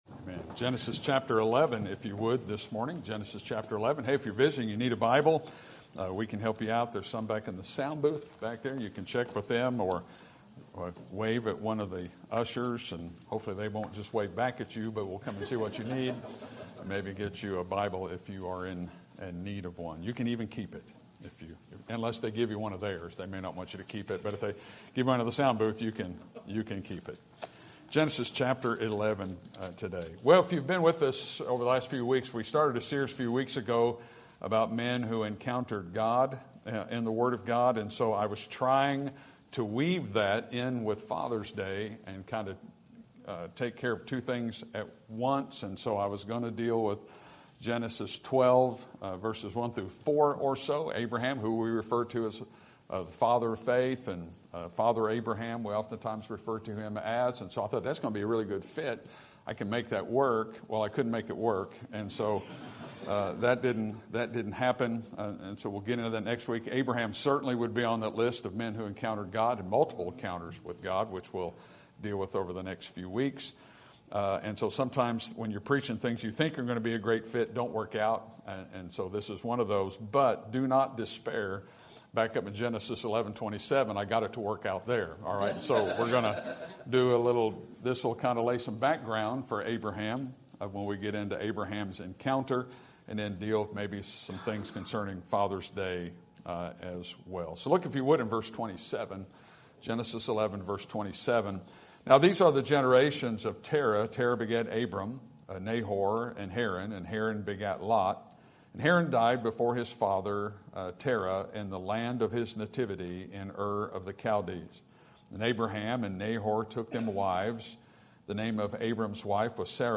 Sermon Topic: General Sermon Type: Service Sermon Audio: Sermon download: Download (22.75 MB) Sermon Tags: Genesis Father Decisions Generations